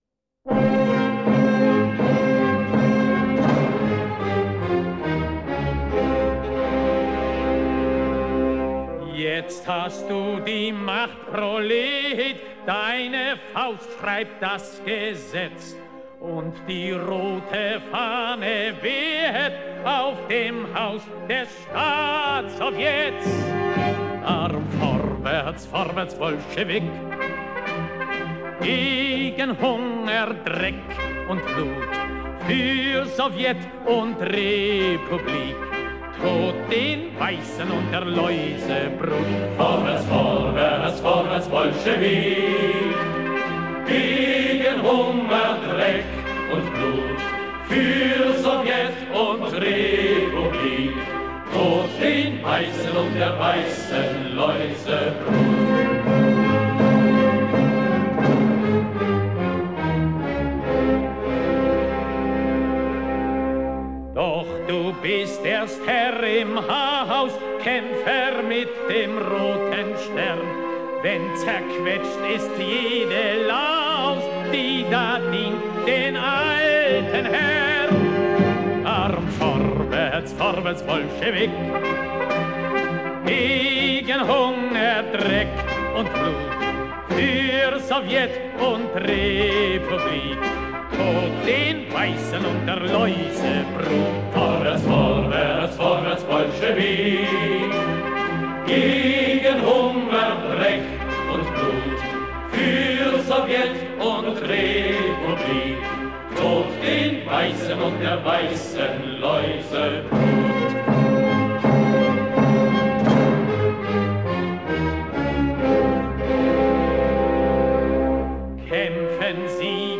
- маршевая немецкая песня